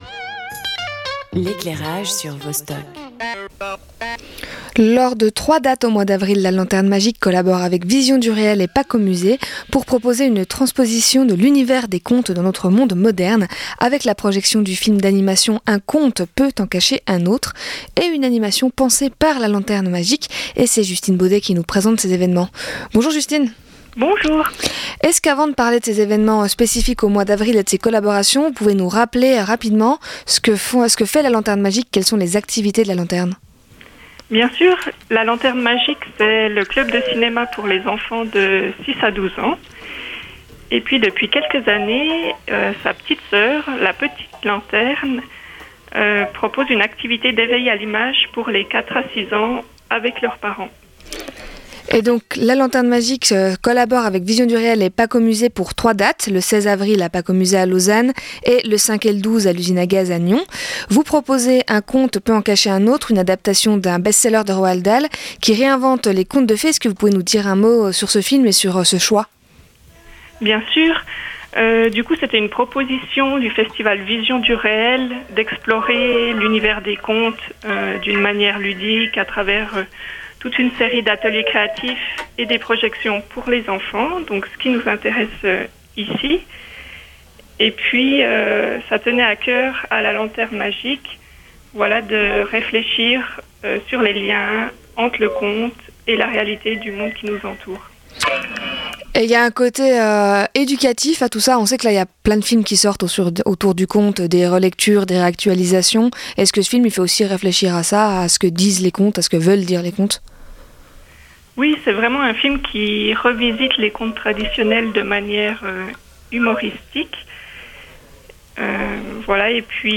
Invitée